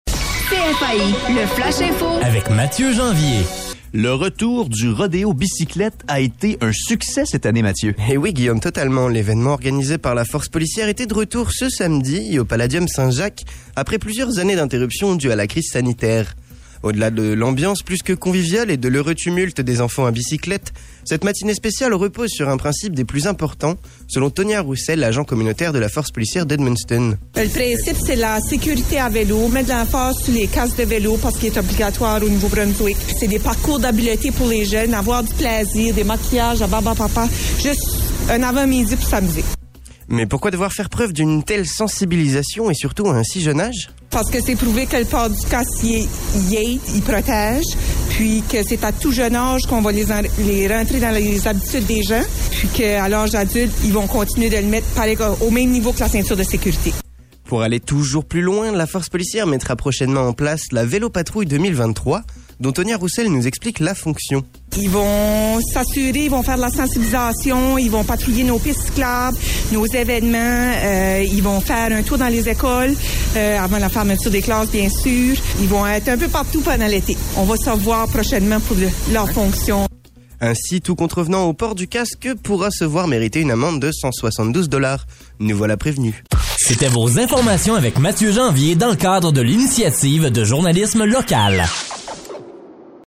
Le bulletin